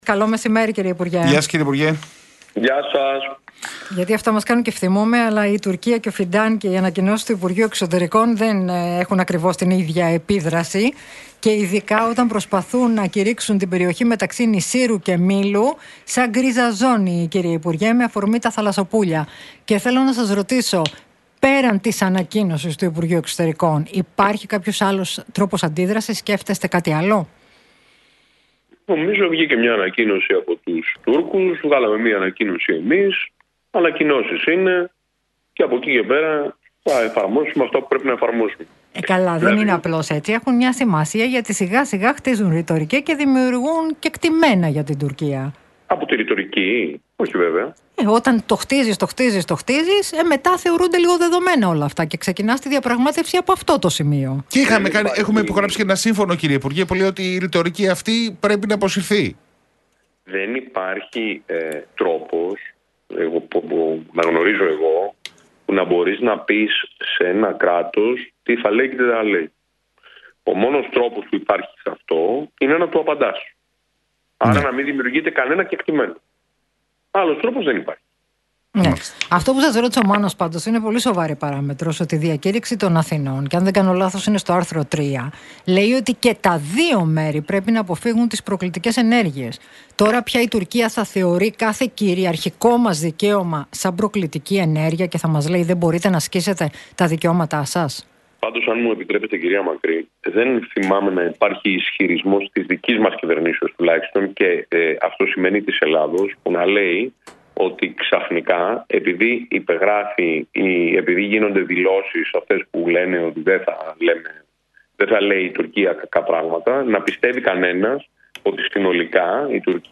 Βορίδης στον Realfm 97,8: Η κυβέρνηση έχει βελτιωμένους δείκτες στο θέμα της ασφάλειας - Τι είπε για Τουρκία και ευρωεκλογές